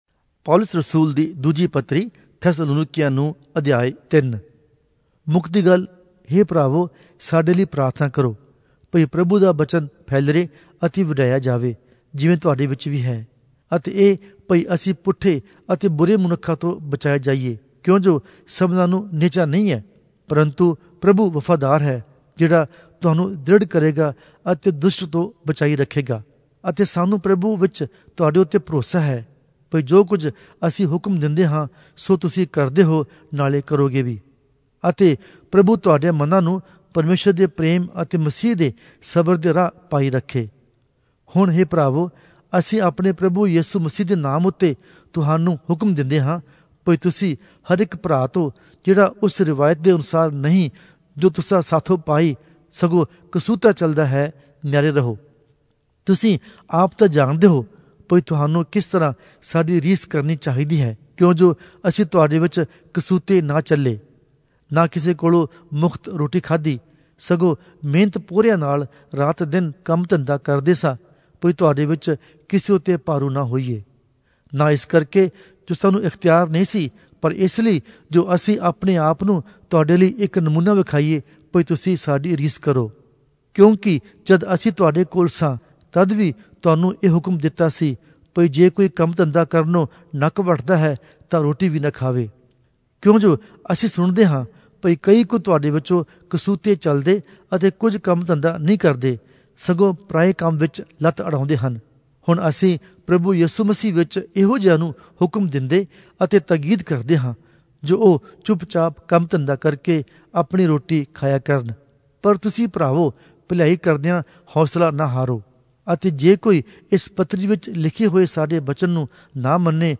Punjabi Audio Bible - 2-Thessalonians 3 in Ervbn bible version